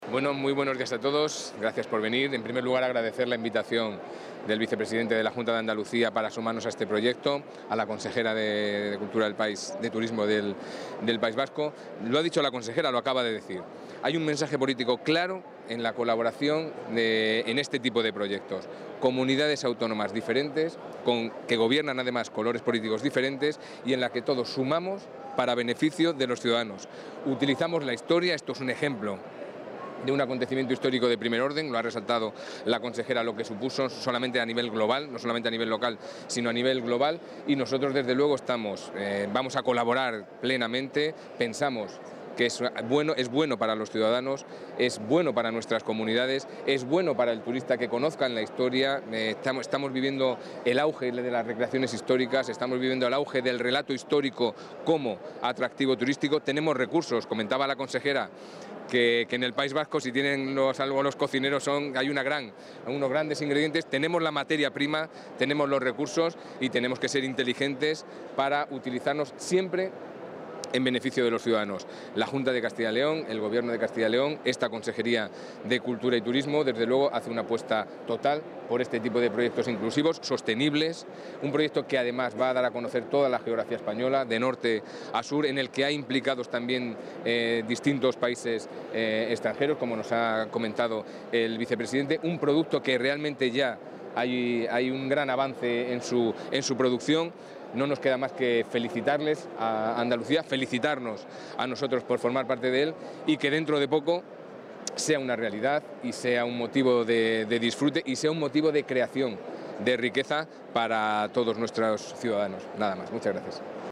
Intervención del consejero de Cultura y Turismo.
La Consejería de Cultura y Turismo participa en FITUR en la presentación del nuevo proyecto de la ruta ‘Magallanes-Elcano’ como itinerario cultural y turístico, junto a las comunidades de Andalucía y País Vasco. Castilla y León tiene como objetivo la puesta en valor del turismo histórico a través de sus personajes más relevantes, contribuyendo a la diferenciación, la especialización y la internacionalización de la oferta turística.